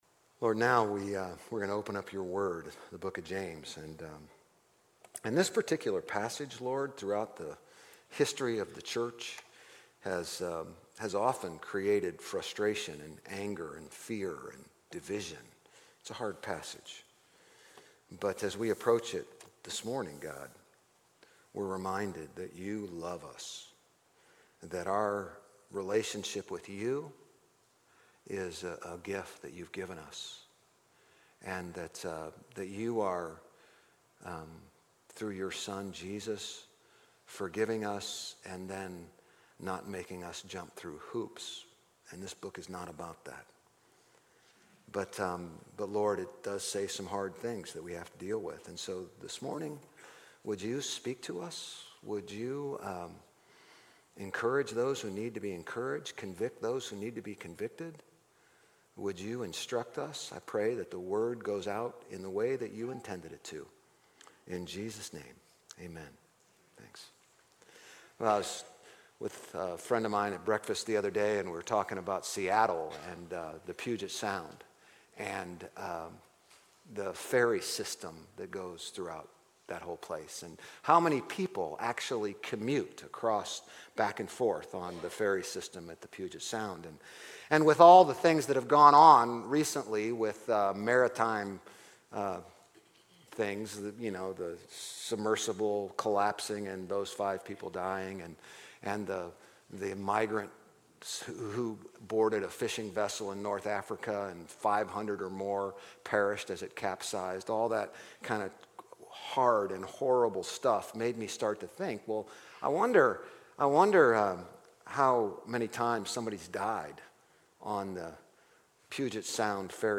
GCC-OJ-July-2-Sermon.mp3